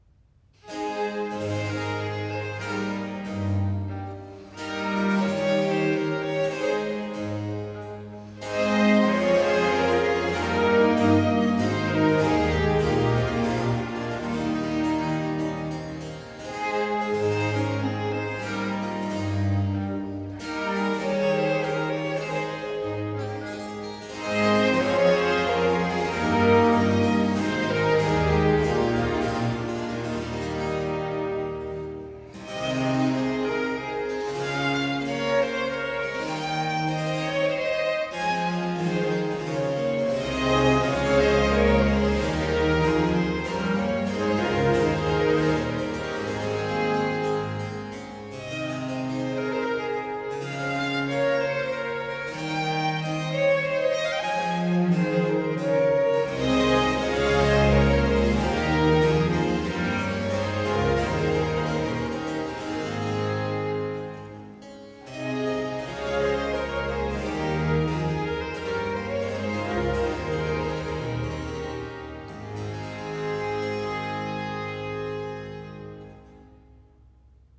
Adagio - andante largo